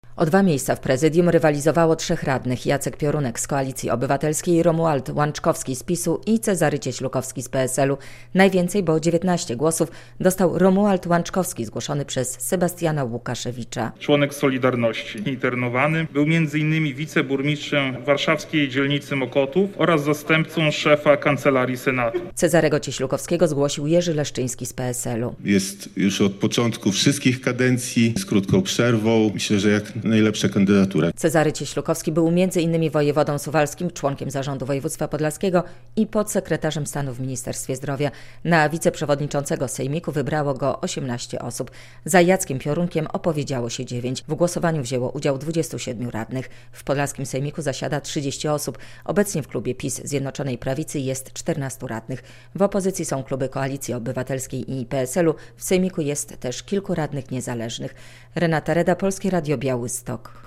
Sejmik województwa podlaskiego ma dwóch nowych wiceprzewodniczących - relacja